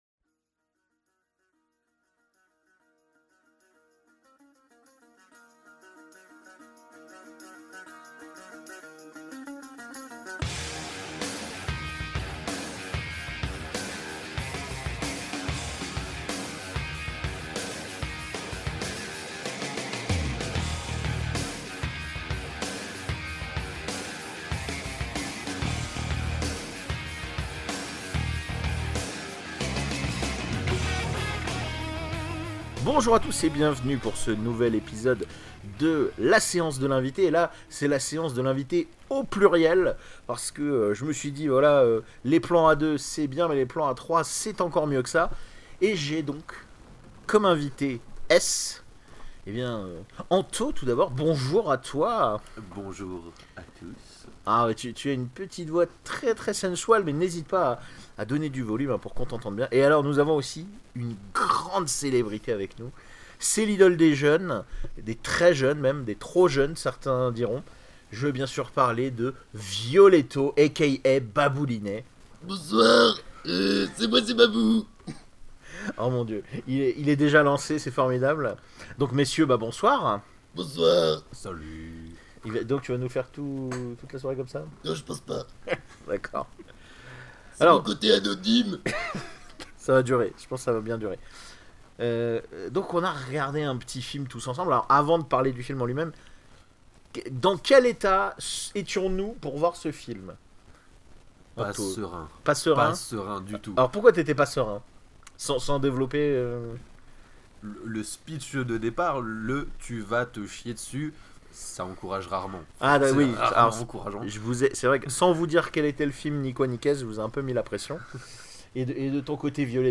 A noté que la configuration à deux invités m’a obligé à changer l’installation technique habituelle de l’émission ce qui à causé pas mal de déchet sur le son (ça reste écoutable je vous rassure) et je vous prie donc de nous en excuser.